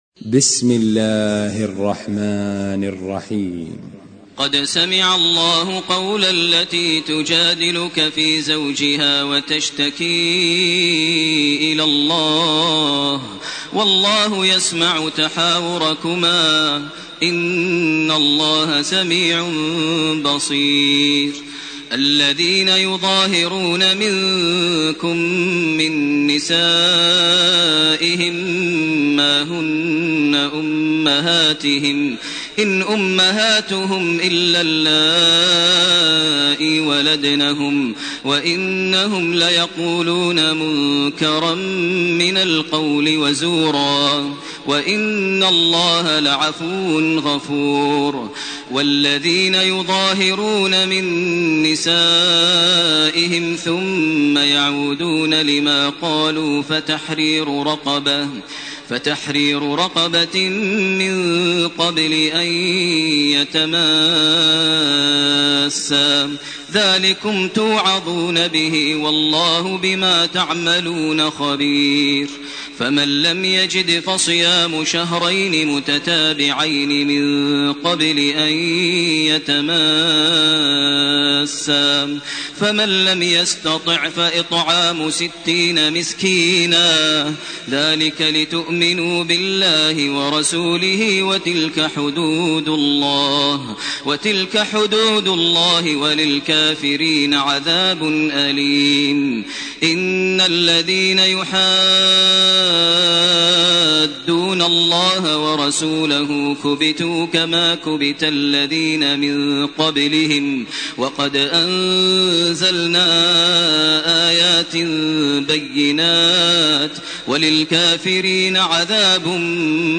سورة المجادلة سورة الحشر سورة الممتحنة سورة الصف > تراويح ١٤٢٨ > التراويح - تلاوات ماهر المعيقلي